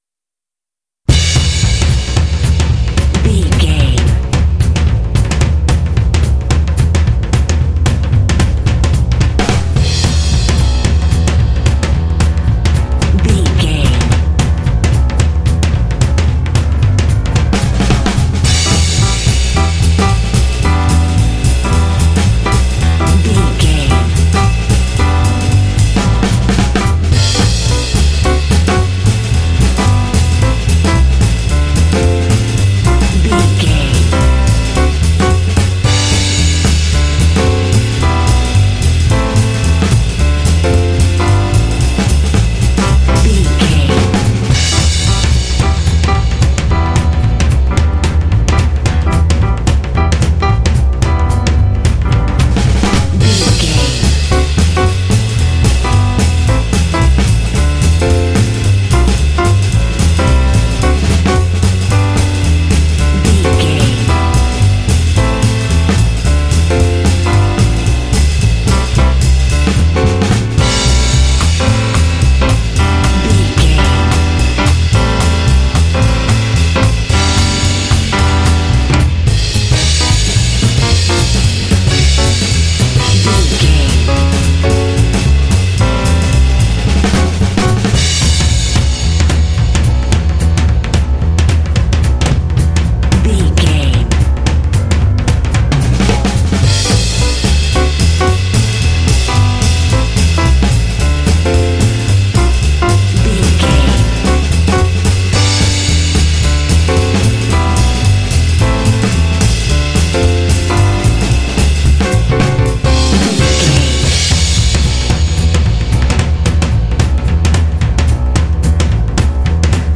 Uplifting
Aeolian/Minor
Fast
energetic
lively
cheerful/happy
drums
piano
double bass
big band
jazz